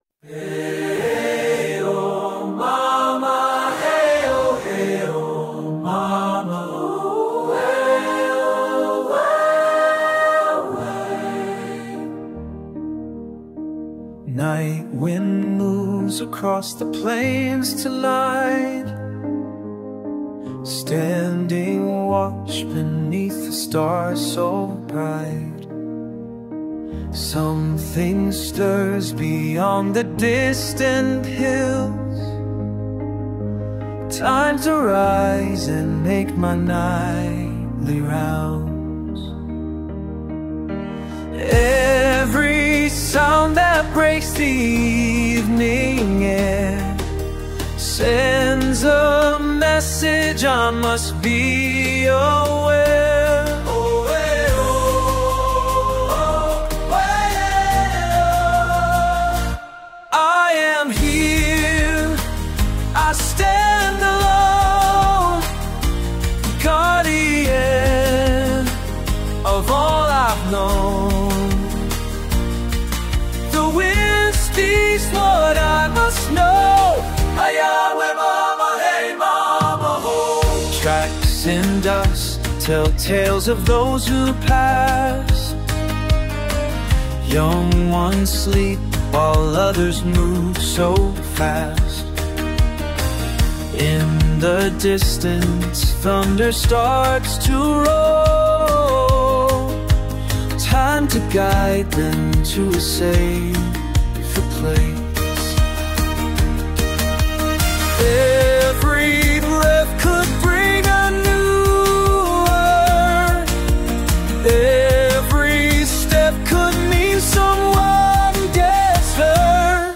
Synthwave năng lượng cao, đèn neon, nhịp điệu lái xe nhanh.
[synthwave]